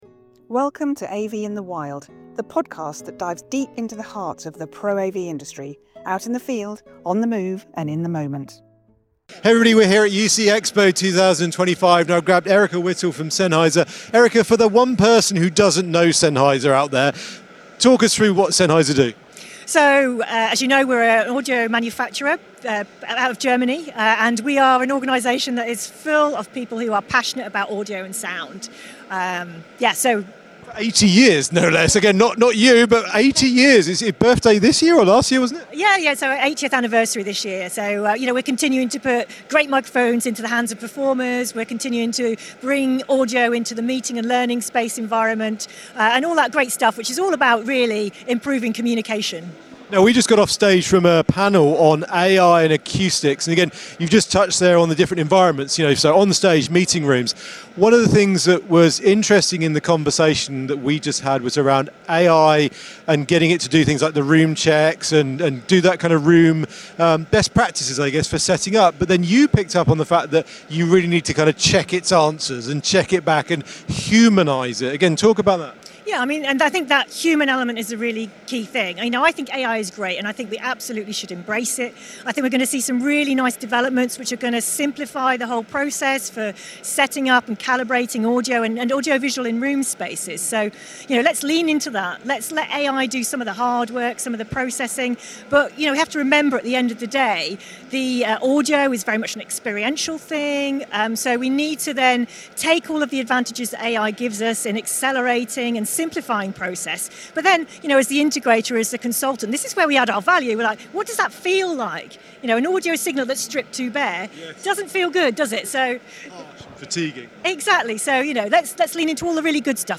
Location: UCX 2025, London